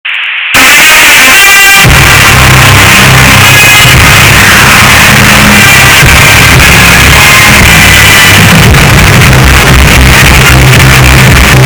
bass boosted nfl badly Meme Sound Effect
bass boosted nfl badly.mp3